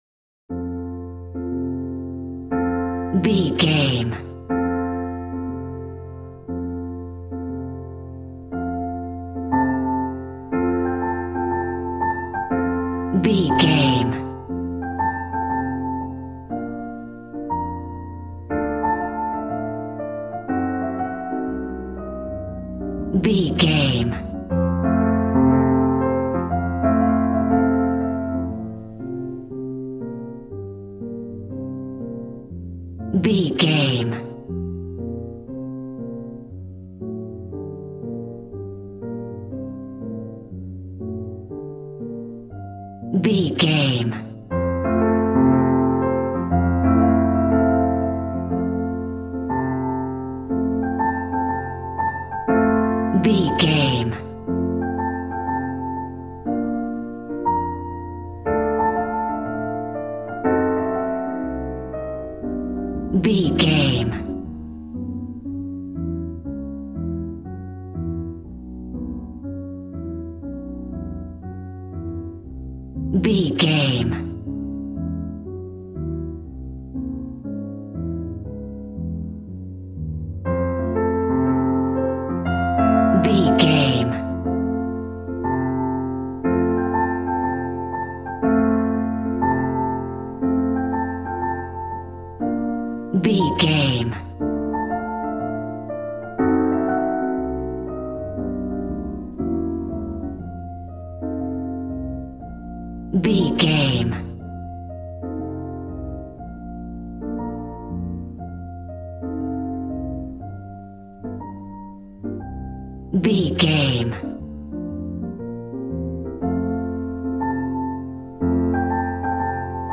Ionian/Major
smooth
drums